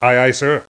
1 channel
windBalloonDrop.mp3